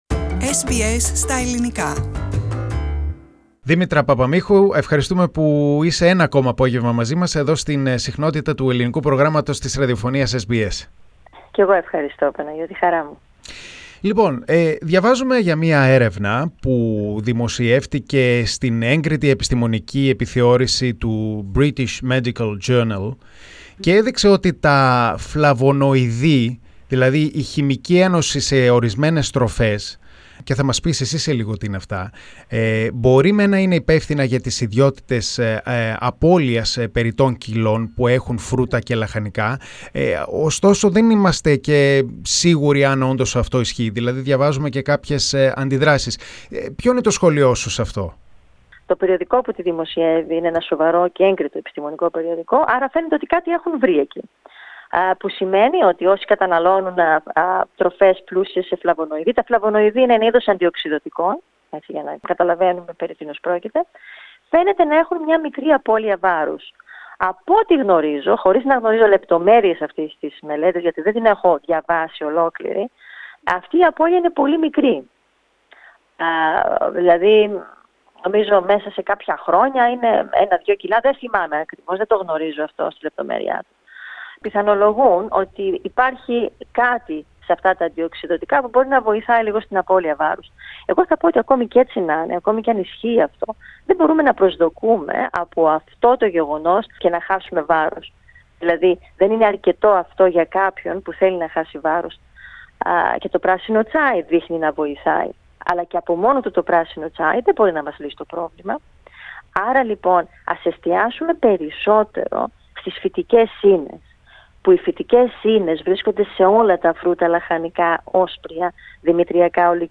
Press the Play button on the main photo and listen to the interview in Greek Follow SBS Greek on Facebook Listen to SBS Greek Podcasts here Share